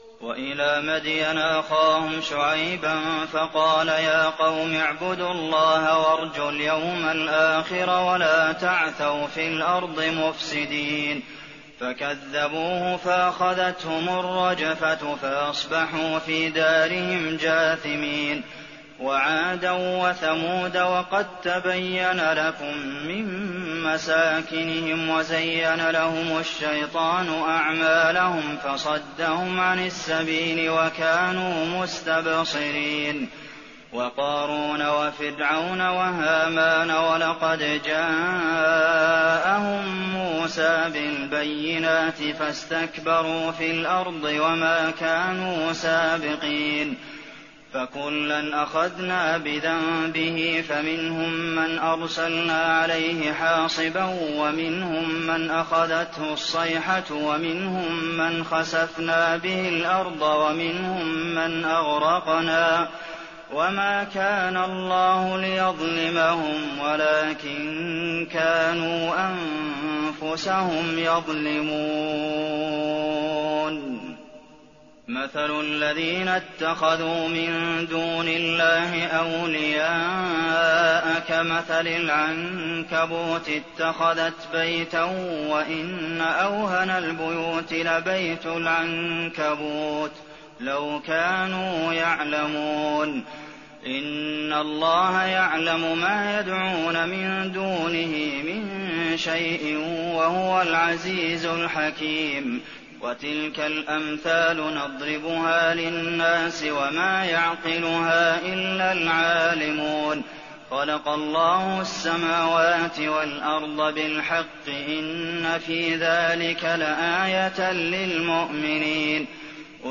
تراويح ليلة 20 رمضان 1419هـ من سور العنكبوت (36-69) و الروم و لقمان (1-11) Taraweeh 20th night Ramadan 1419H from Surah Al-Ankaboot and Ar-Room and Luqman > تراويح الحرم النبوي عام 1419 🕌 > التراويح - تلاوات الحرمين